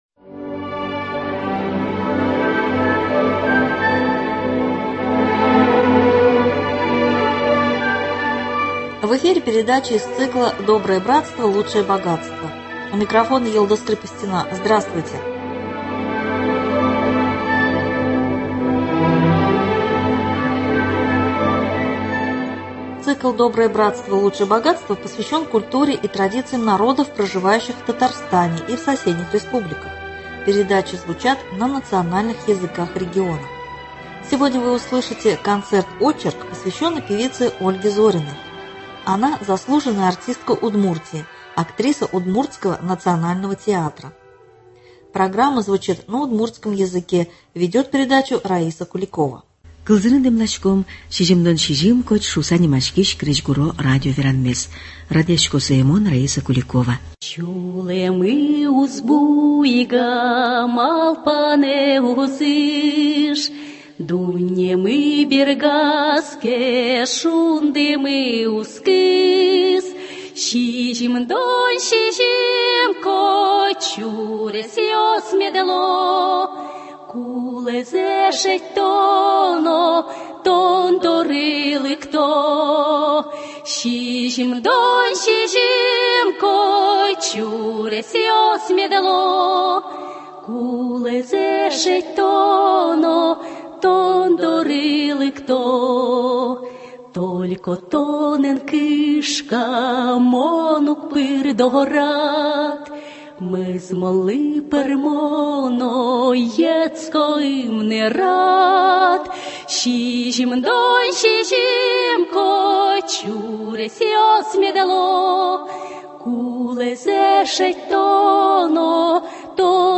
(из фондов радио).